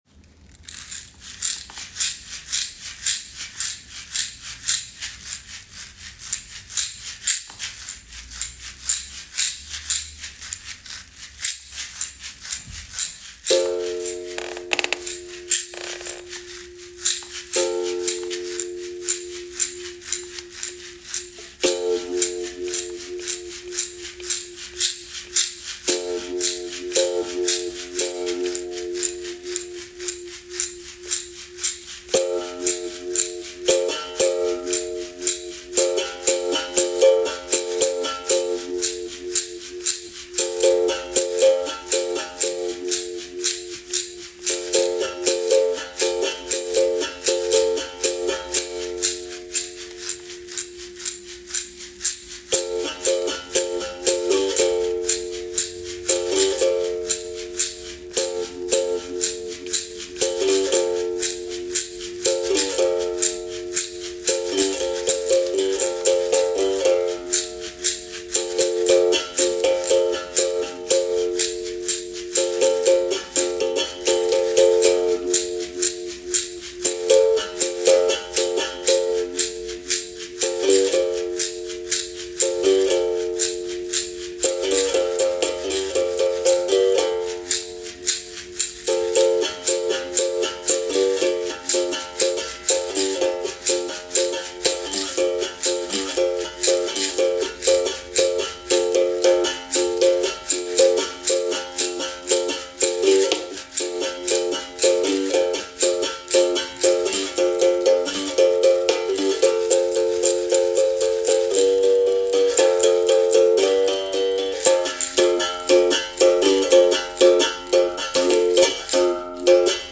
en Drawing on the morphing structure which encourages tonotice something about a movement and morph it into that direction, I’ve explored the Afro-Brazilian instrument berimbau.
en Berimbau
en 8. Sound/music